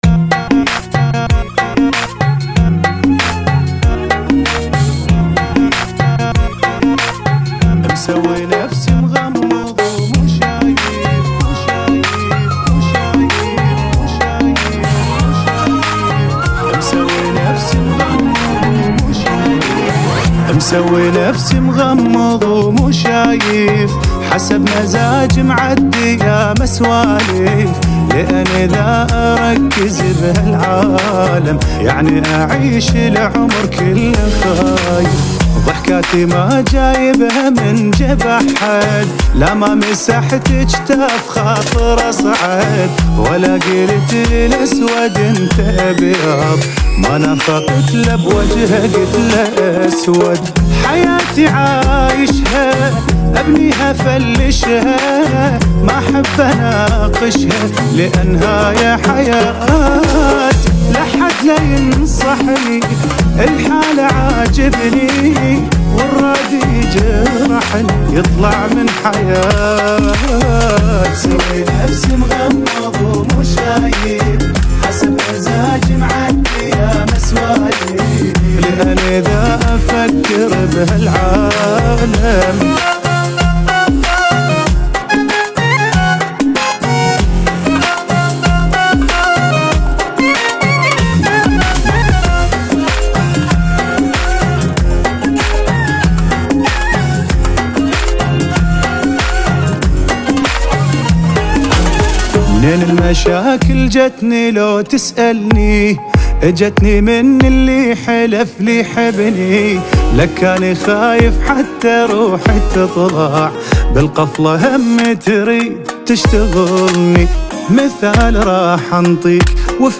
[ 95 bpm ]
No Drop - بدون جنقل